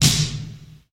• 80s Long Acoustic Snare A Key 64.wav
Royality free snare sample tuned to the A note.